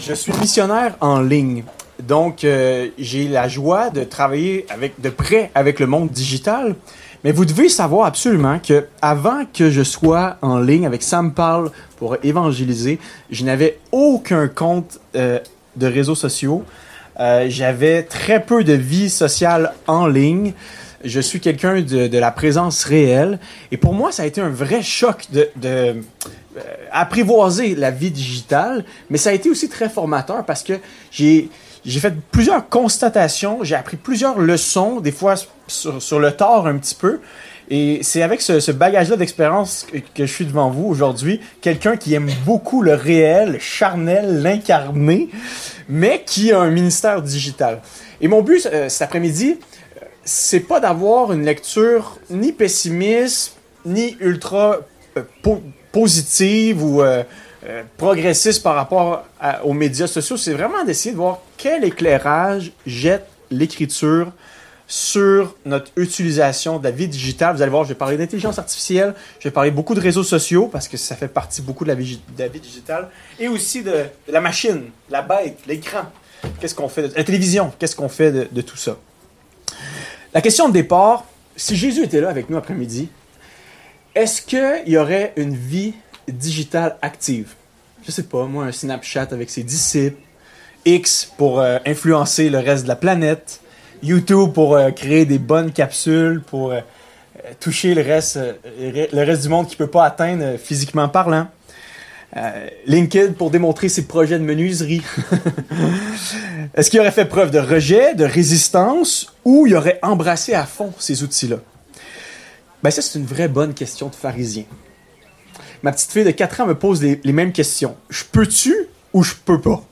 Ateliers Pâques 2025, Vivant